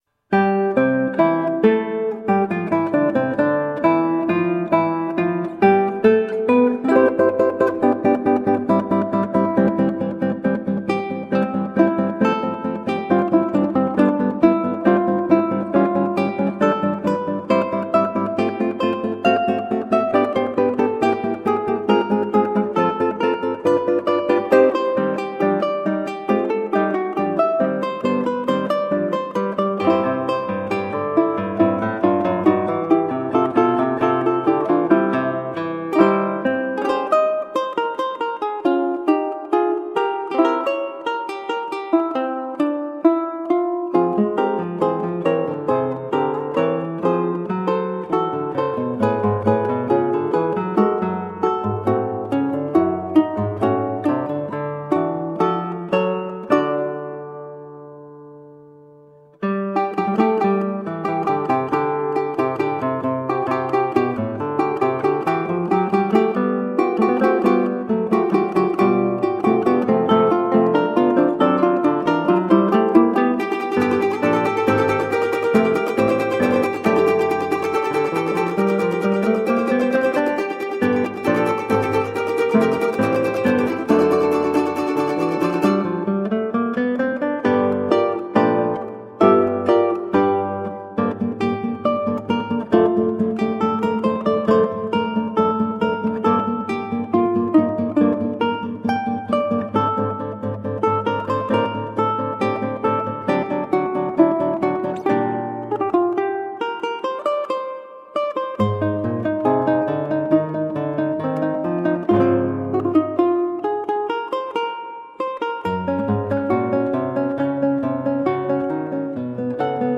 Colorful classical guitar.